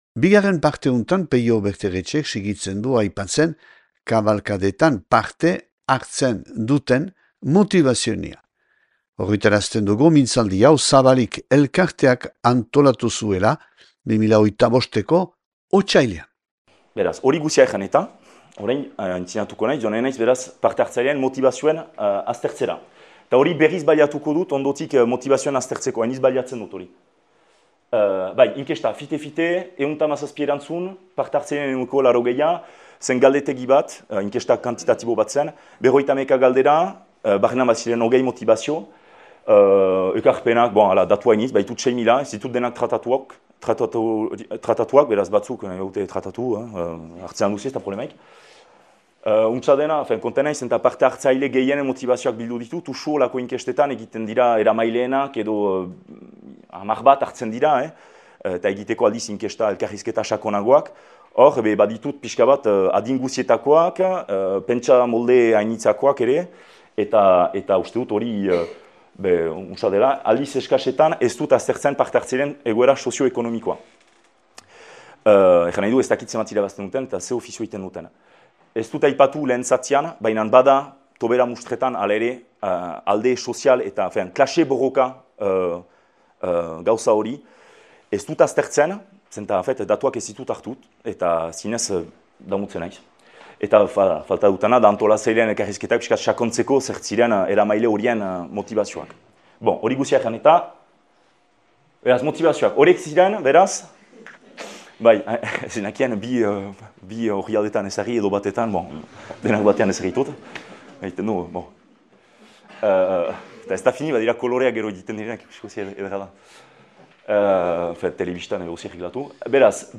(2025. otsailaren 20an grabatua Donapaleun « Otsail Ostegunak » hitzaldiaren zikloan Zabalik elkarteak.